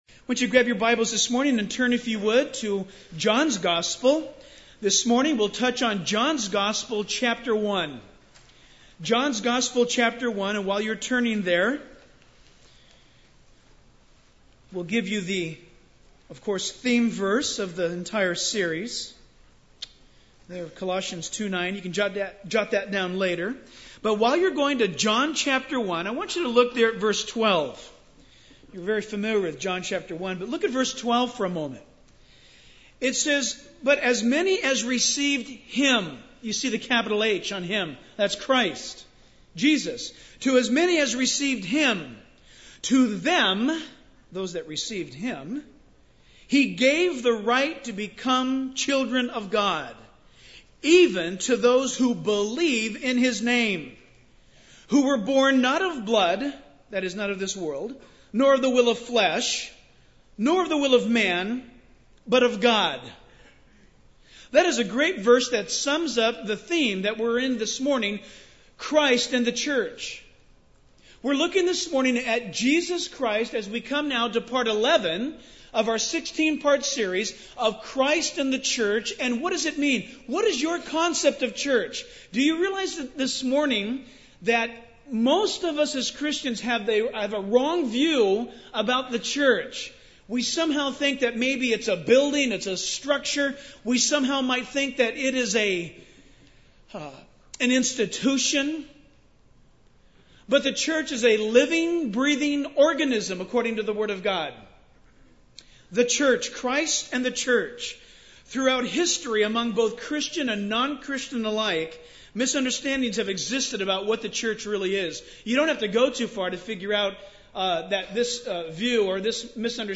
In this sermon, the preacher emphasizes the importance of the church and its mission in the lives of believers. He encourages the congregation to have faith and trust in God, rather than relying on their own efforts. The preacher highlights three divisions of believers throughout history: the Old Testament saints, the church, and the future believers.